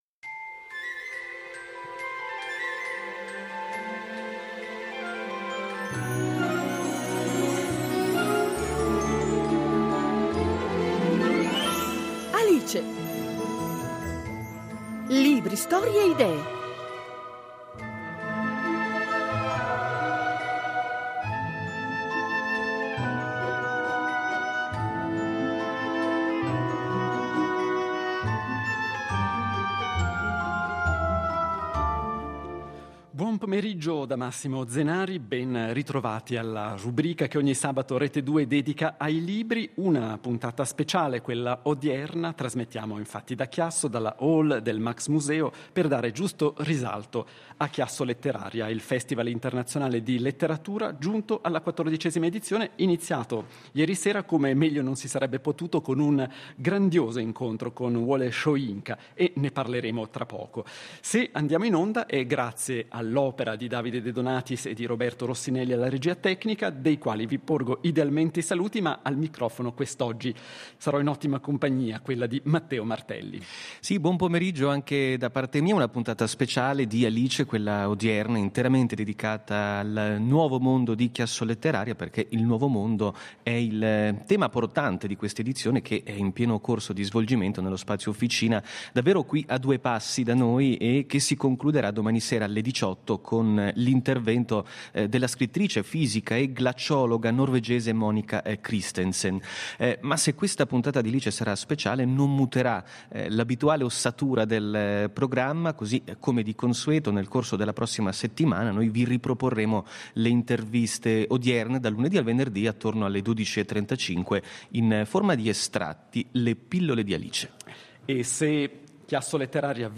In diretta da "Chiasso/Letteraria"